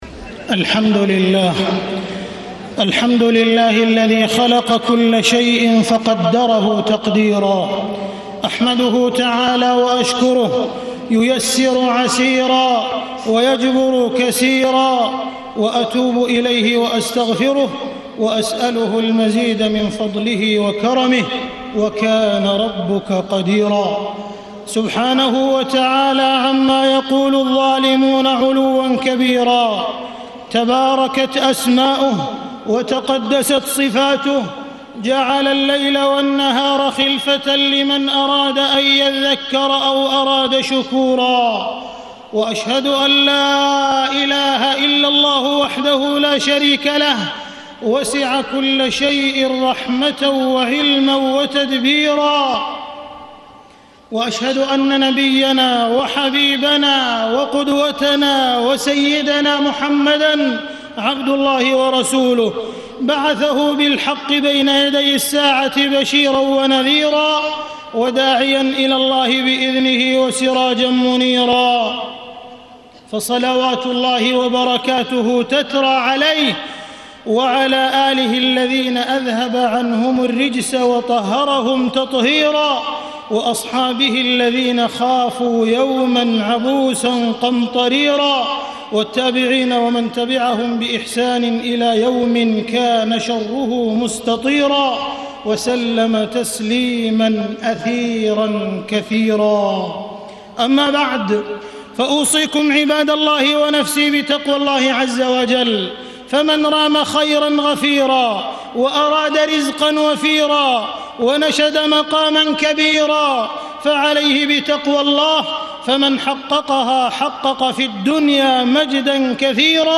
تاريخ النشر ٢ محرم ١٤٢٦ هـ المكان: المسجد الحرام الشيخ: معالي الشيخ أ.د. عبدالرحمن بن عبدالعزيز السديس معالي الشيخ أ.د. عبدالرحمن بن عبدالعزيز السديس دروس من التاريخ الإسلامي